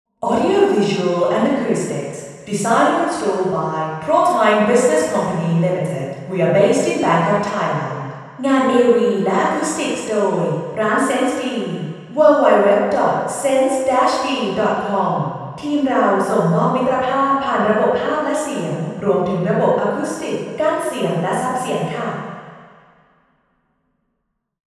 Room: Vethes Samosorn, Ministry of Foreign Affairs
Test Position 2: 8 m